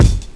INSKICK07 -R.wav